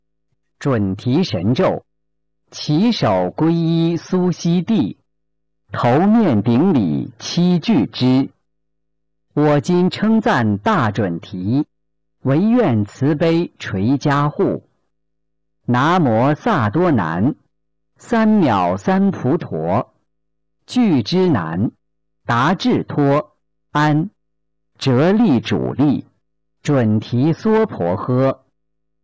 007《准提神咒》教念男声